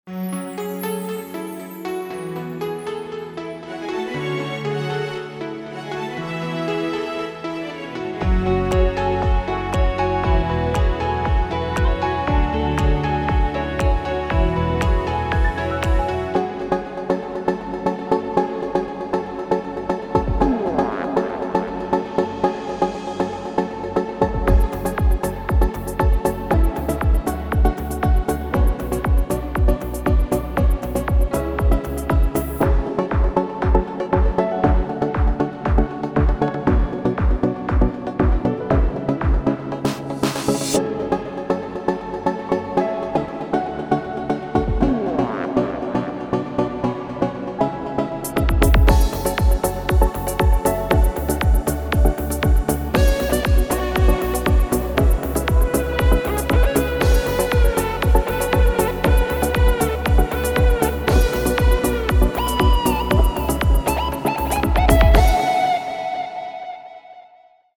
06 Slow
אסטה-אלקטרוני_05.mp3